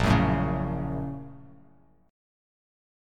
A#mM7#5 Chord
Listen to A#mM7#5 strummed